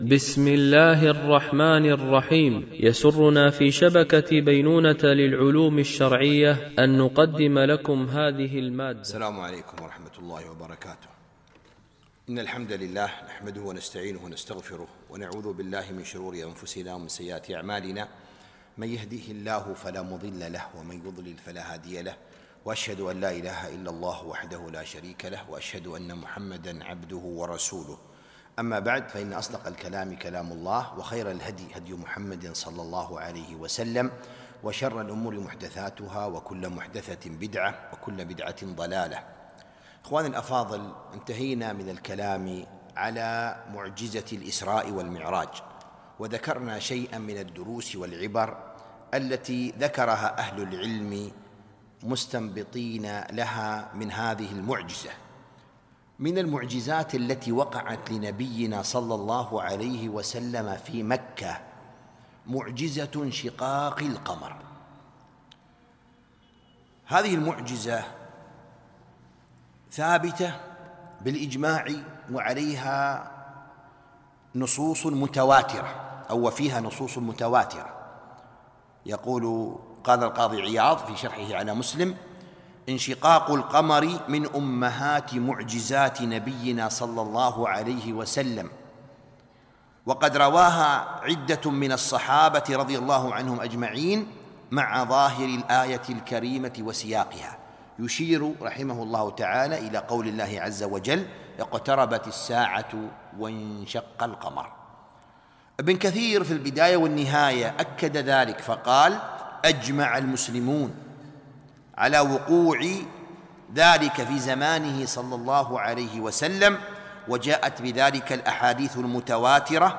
الدروس والعبر من سيرة خير البشر ـ الدرس 14